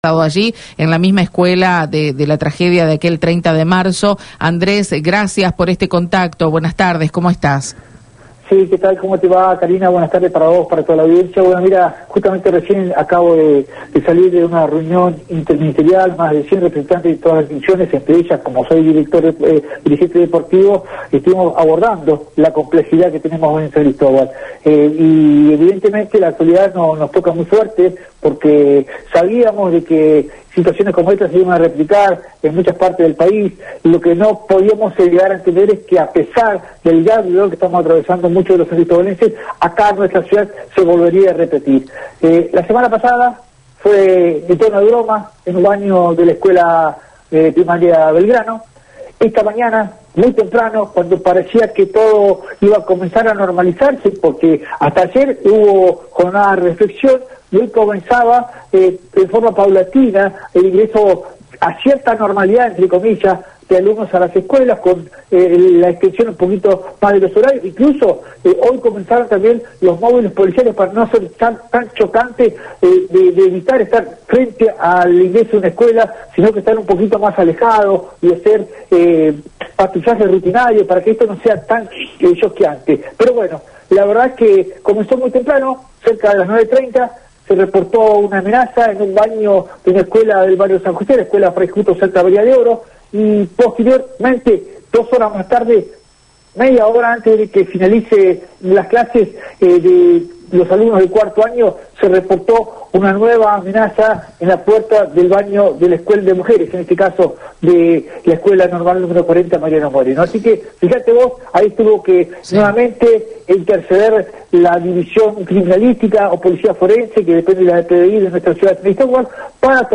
El entrevistado confirmó que no se trató de un hecho aislado, ya que durante la misma mañana se registraron otras intimidaciones en diferentes instituciones educativas.